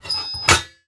metal_lid_movement_impact_02.wav